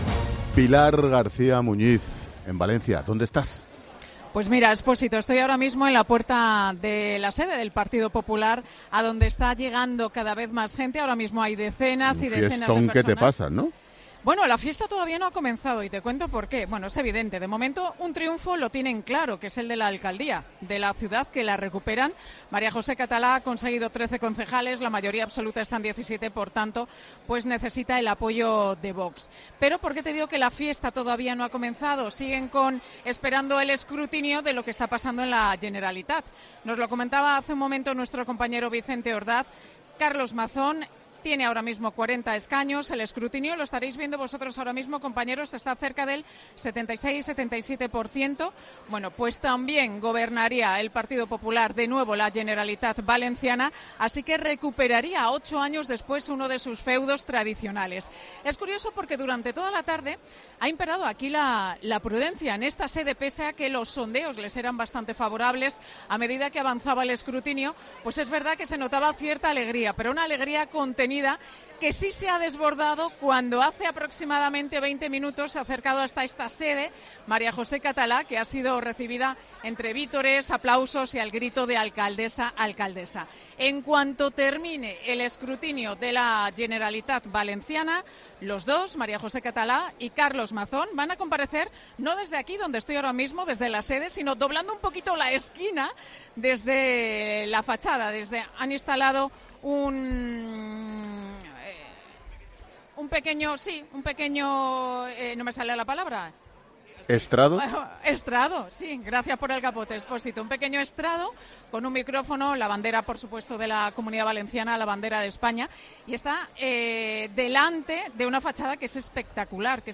Pilar García Muñiz desde la sede del PP en Valencia